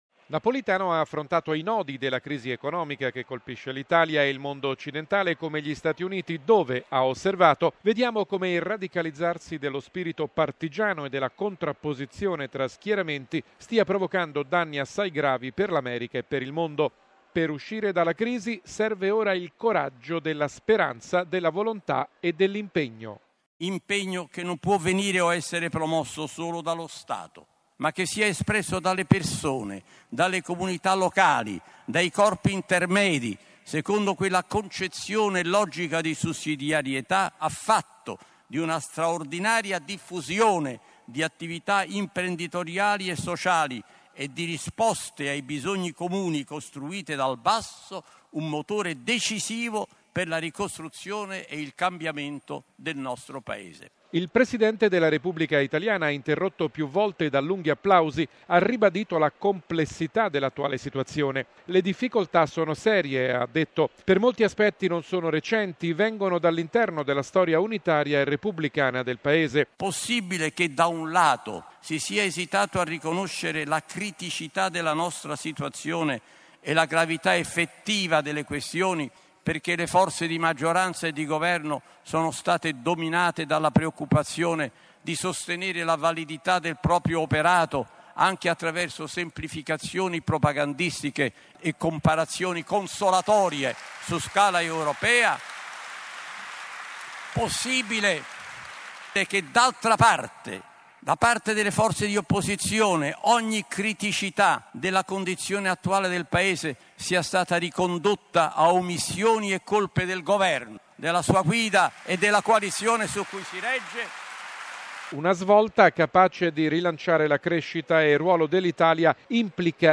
Il presidente della Repubblica italiana, interrotto più volte da lunghi applausi, ha ribadito la complessità dell’attuale situazione.“Le difficoltà sono serie, ha sottolineato, per molti aspetti non sono recenti, vengono dall’interno della storia unitaria e repubblicana del Paese":